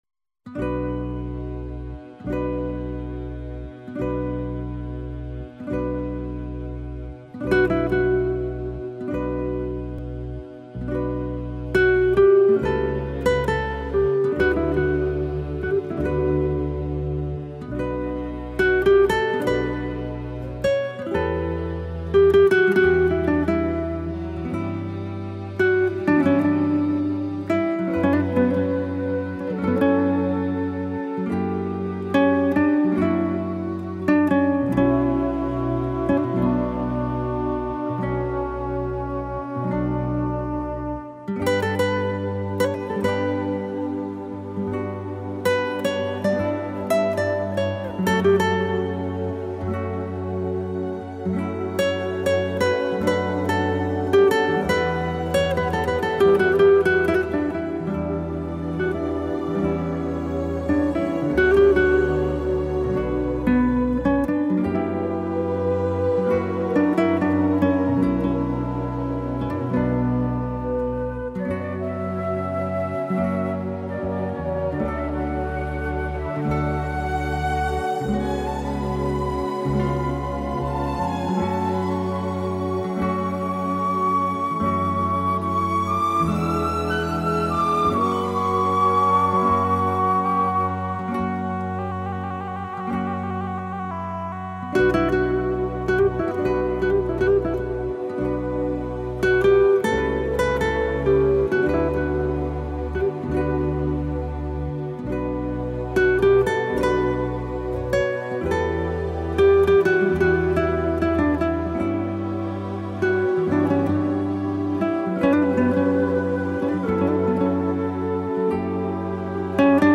دانلود اهنگ بیکلام